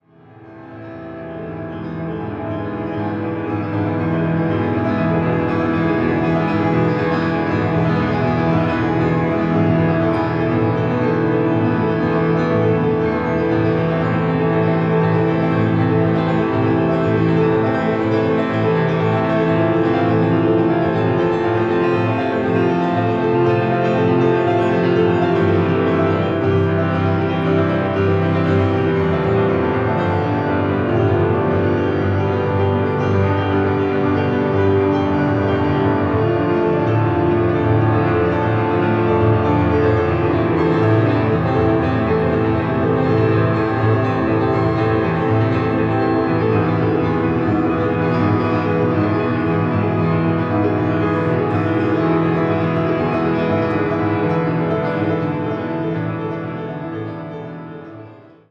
For Two Pianos
Winnipeg, Manitoba, Canada